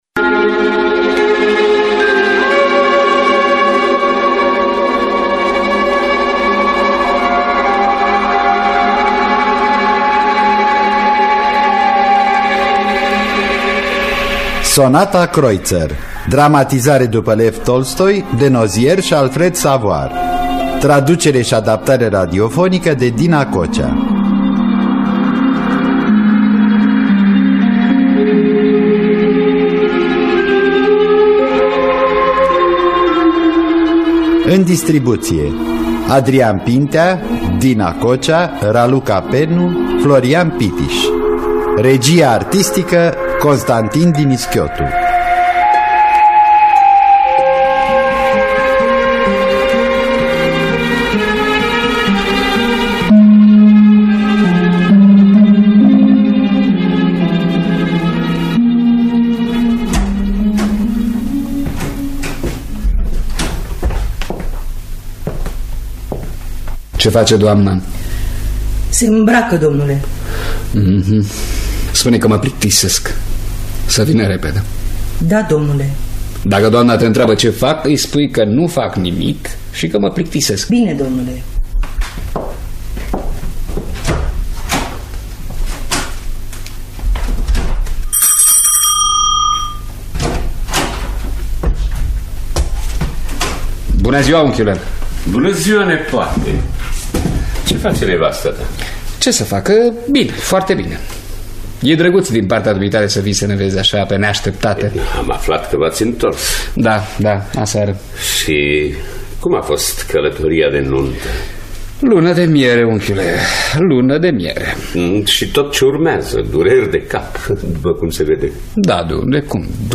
Sonata Kreutzer de Lev Nikolaevici Tolstoi – Teatru Radiofonic Online
“Sonata Kreutzer” de Lev Nikolaevici Tolstoi. Traducerea și adaptarea radiofonică de Dina Cocea.